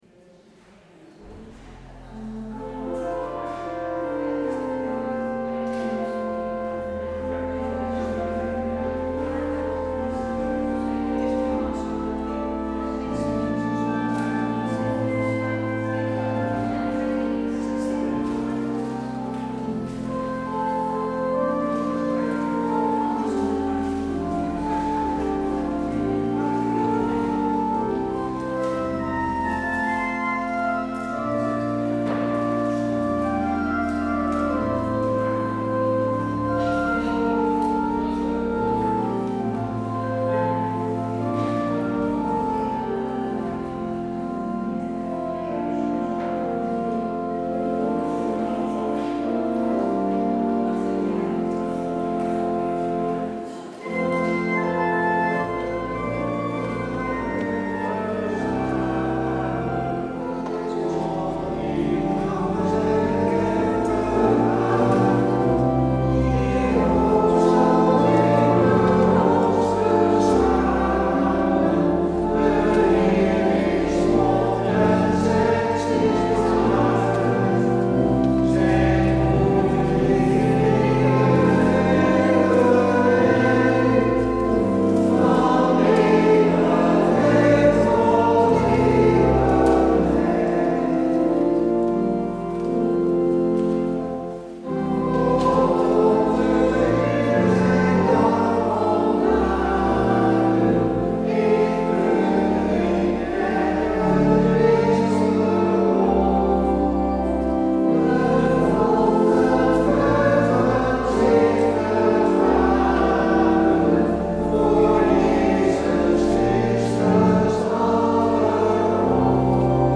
Luister deze kerkdienst hier terug
Als openingslied: Nieuw liedboek Lied 283 : 1, 2 en 3. Het slotlied: Nieuw liedboek Lied 919.